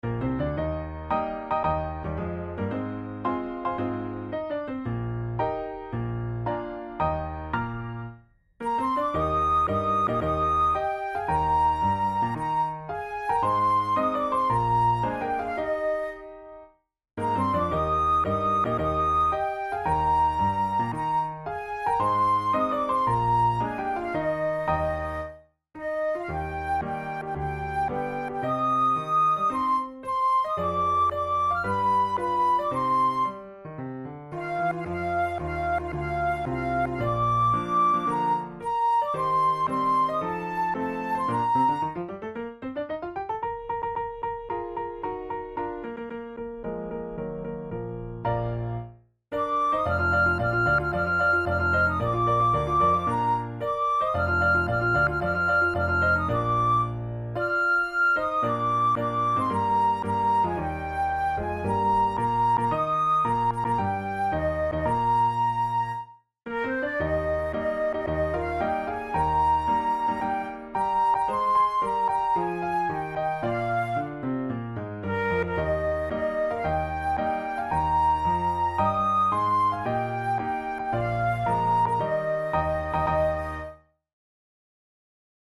InstrumentationFlute and Piano
KeyE-flat major
Tempo112 BPM
arranged for flute and piano